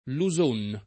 [ lu @1 n ]